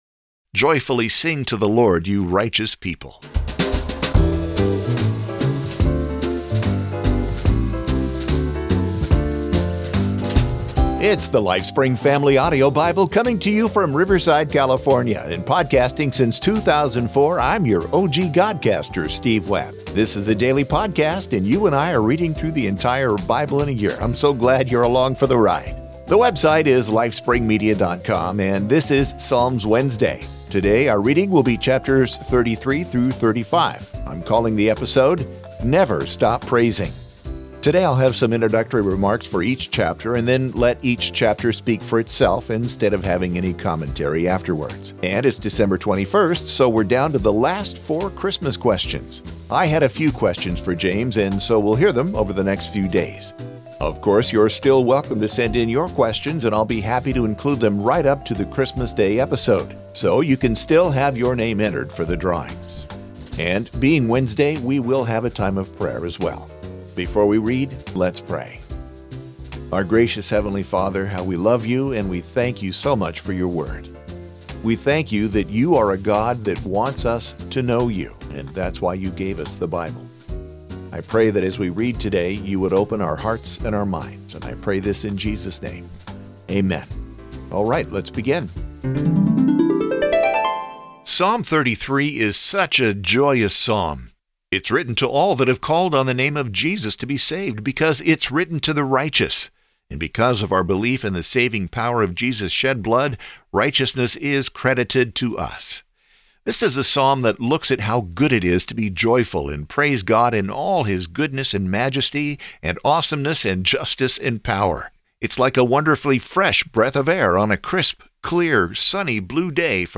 Each episode features a reading, followed by a short commentary.